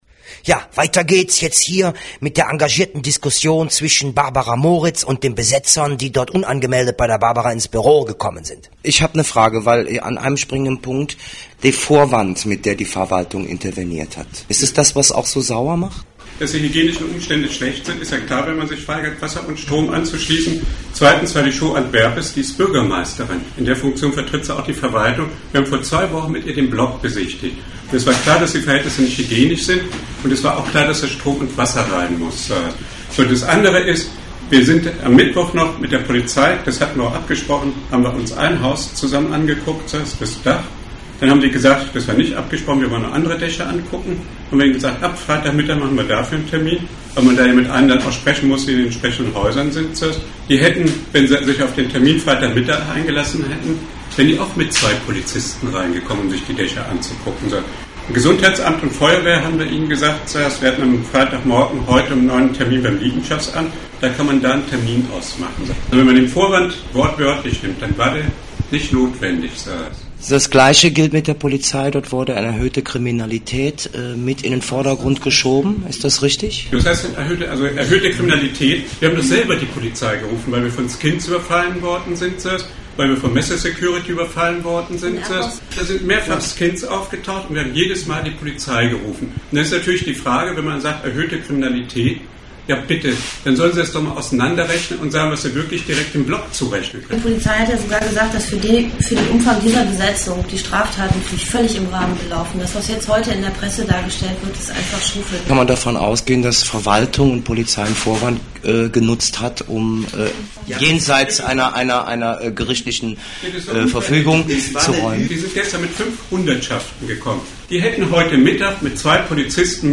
Die NRhZ dankt RADIO FloK und der Redaktion VOX POPULI für diesen Beitrag, aber auch Barabra Moritz, die den Mitschnitt der engagierten Diskussion in ihrem Büro gestattet hat.